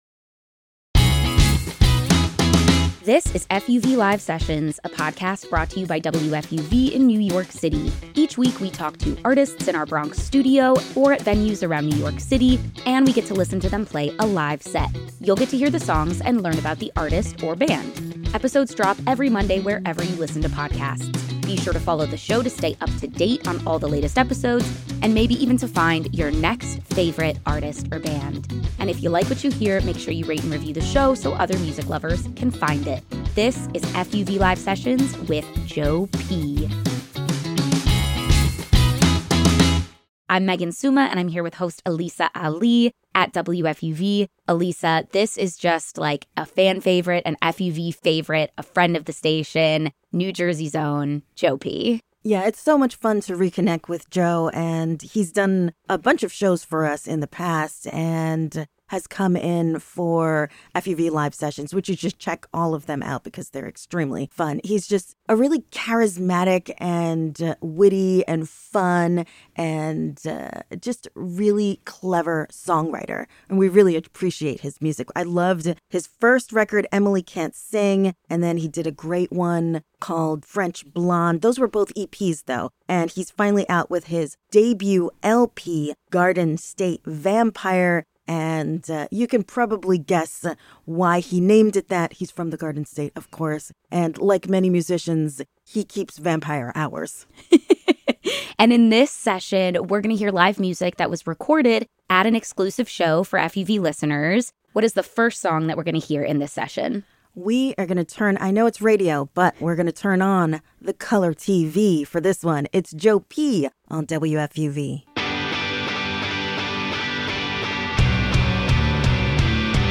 Songs